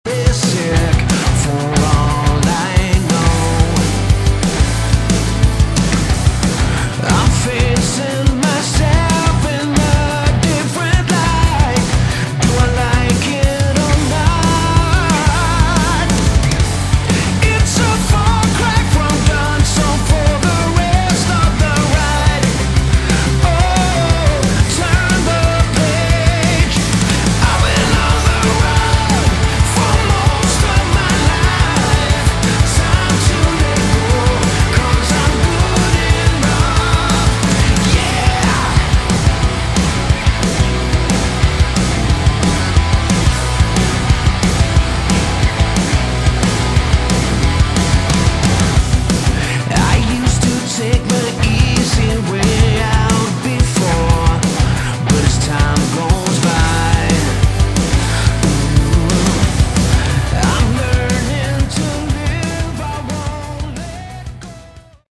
Category: Melodic Rock
Vocals, Bass
Drums
Keyboards
Guitars